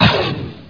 shot06.mp3